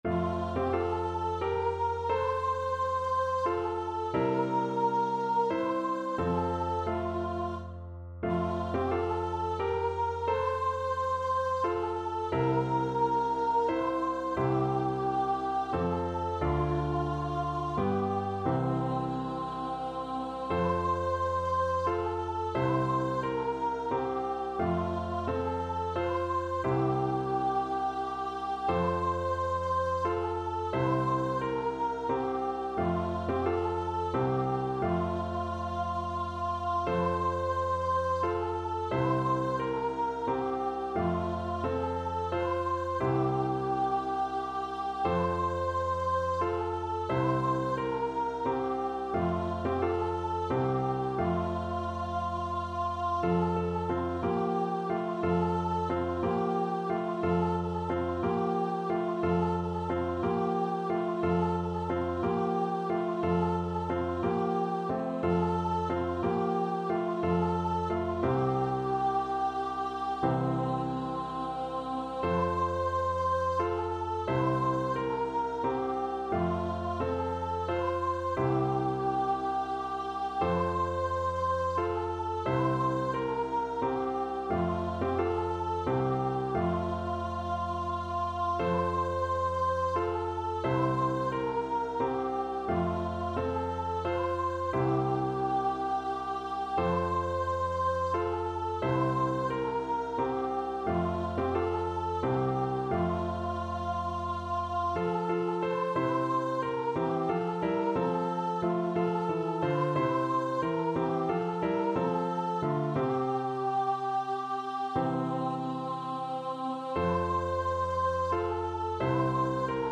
Traditional Music of unknown author.
Andante =c.88
3/4 (View more 3/4 Music)
Swiss